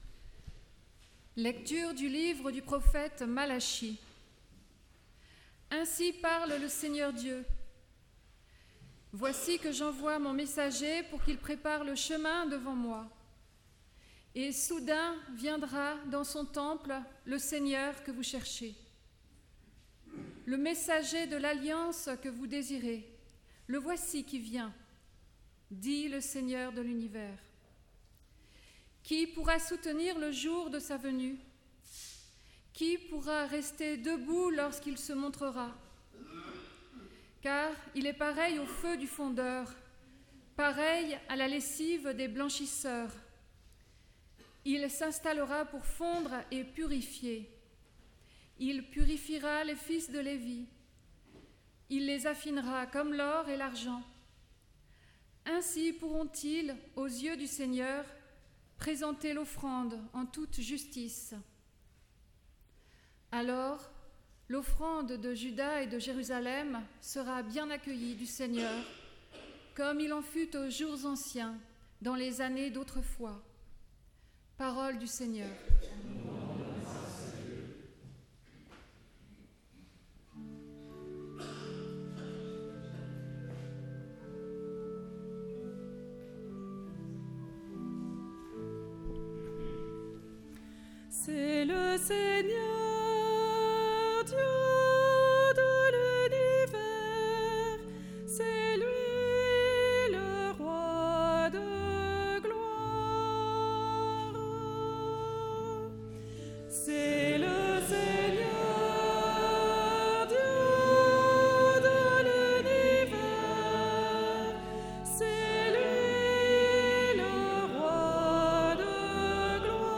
Sermon – Page 2 – Notre-Dame de Nazareth
Parole-de-Dieu-Sermon-du-2-fevrier-2026.mp3